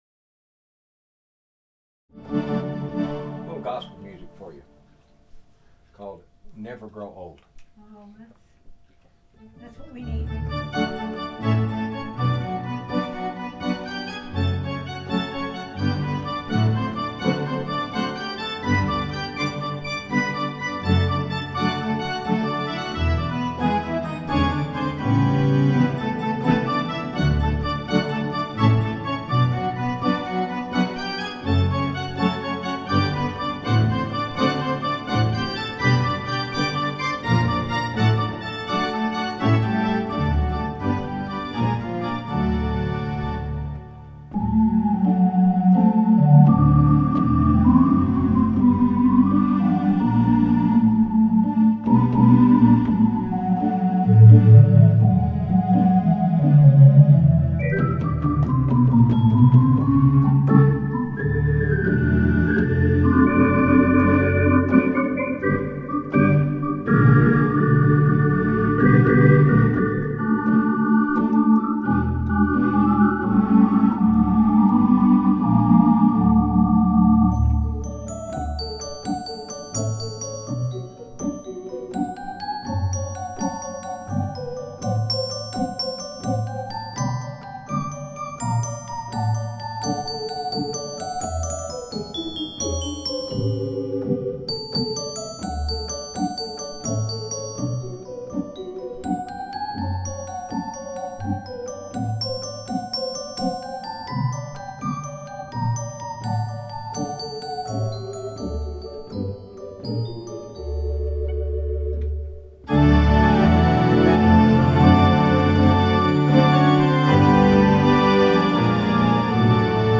Roland Atelier AT90-SL Digital Residence Organ.
In some of the tracks, listed below, you can hear cups being sat down on the table.
Also, since this is totally unrehearsed music, there are a number of mistakes, but hey, it's live and impromptue.